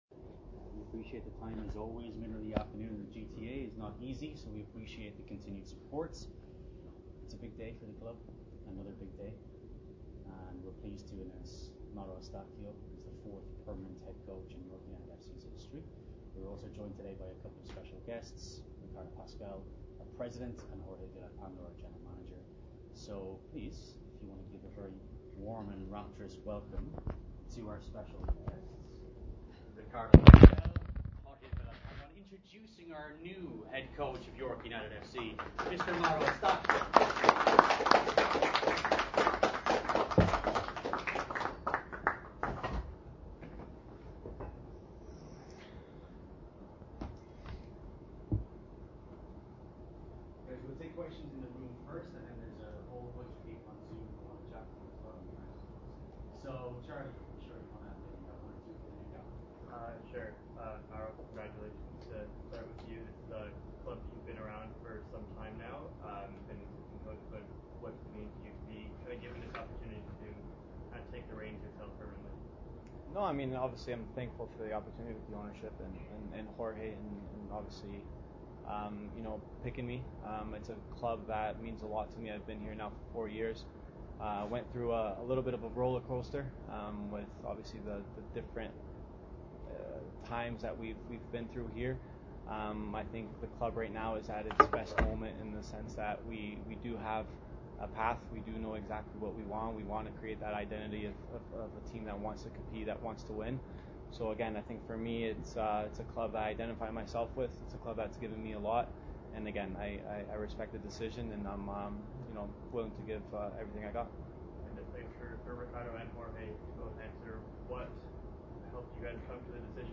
Audio of the press conference... (20 minutes, 25 seconds) starts out sort of quiet in the volume department. What does it feel like to take the reins as the head coach?